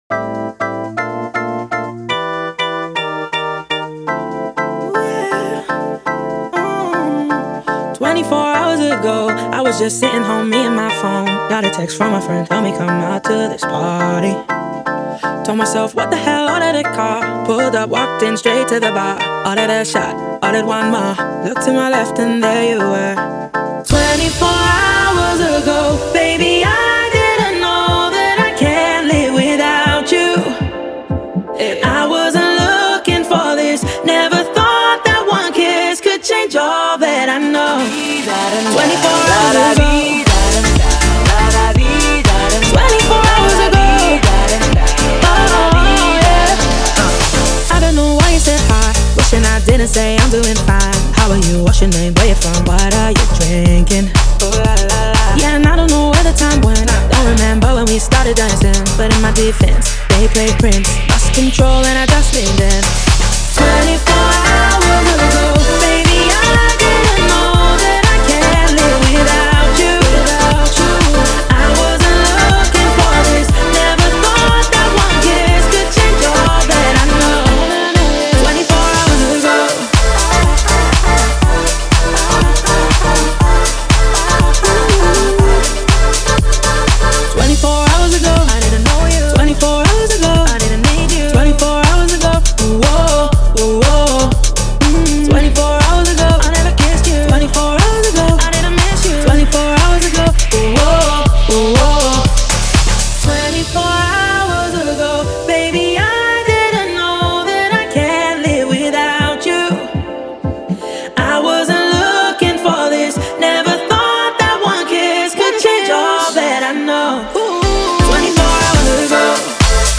это энергичный трек в жанре электронной танцевальной музыки
Машап выделяется оригинальным звучанием